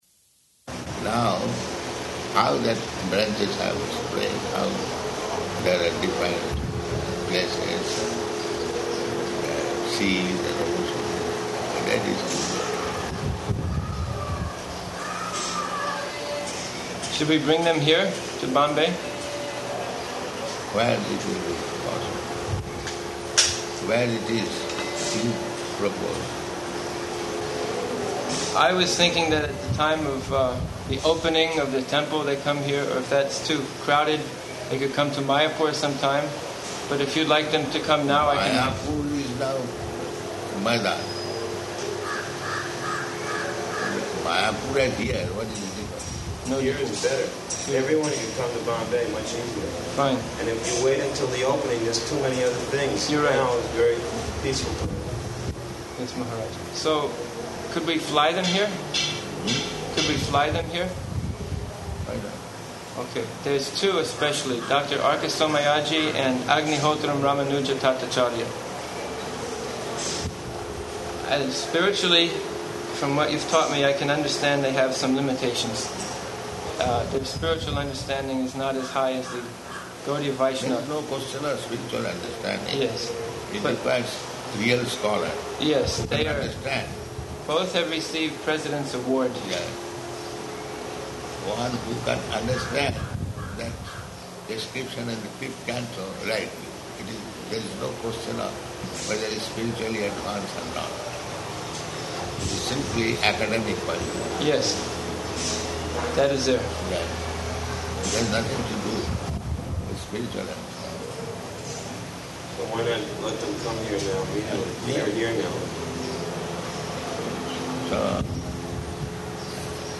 Type: Conversation
Location: Bombay